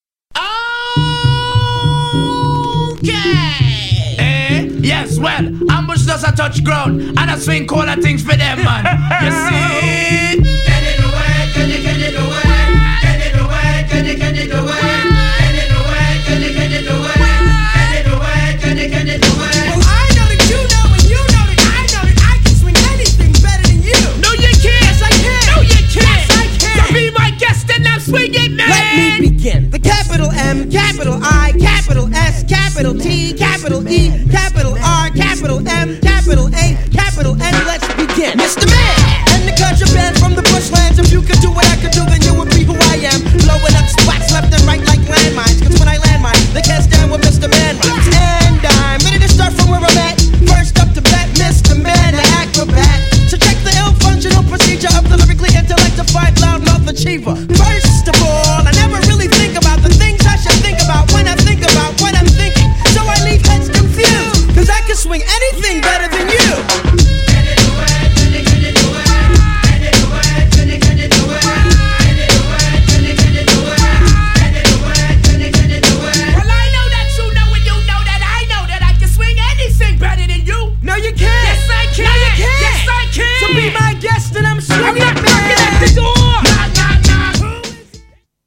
JAZZINESSバージョンも渋い!!
GENRE Hip Hop
BPM 106〜110BPM